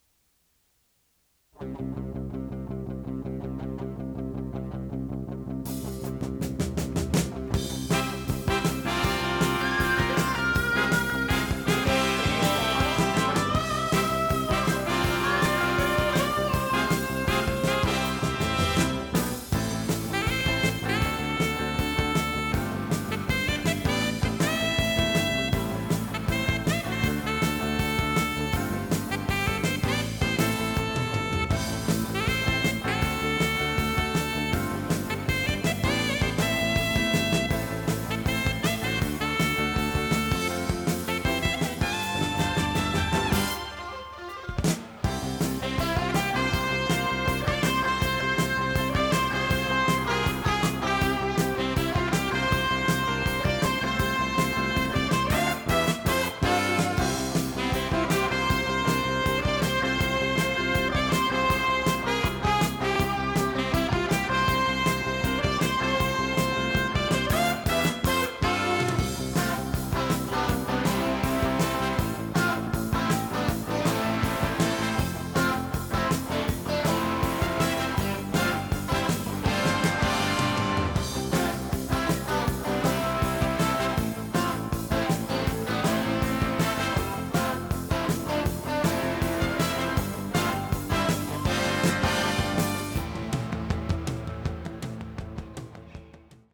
○上級機よりも音質の癖が少なく場合によっては聴きやすいことも（上級機は低音が強め）
テープ：RTM C-60（現行品）
ノイズリダクションOFF
【フュージョン・ロック】容量53.0MB